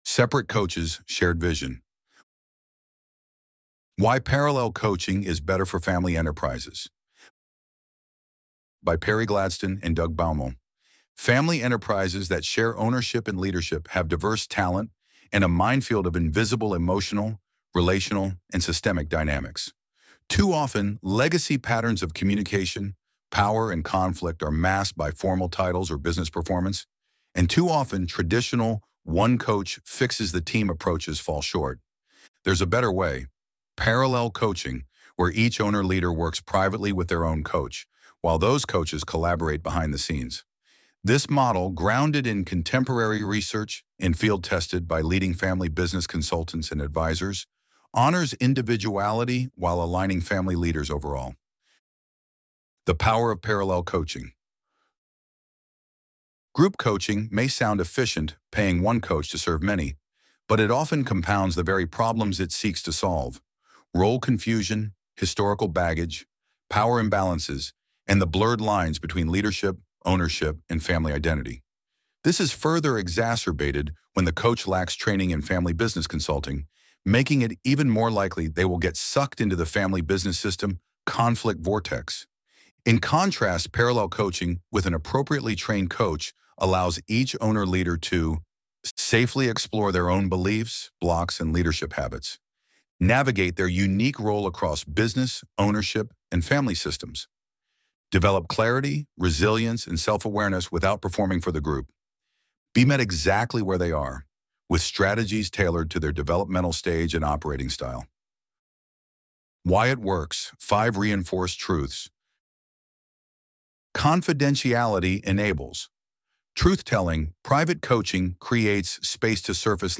Separate Coaches, Shared Vision Why Parallel Coaching Beats Group Coaching for Family‑Led Enterprises Loading the Elevenlabs Text to Speech AudioNative Player...